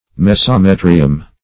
Search Result for " mesometrium" : The Collaborative International Dictionary of English v.0.48: Mesometrium \Mes`o*me"tri*um\, n. [NL.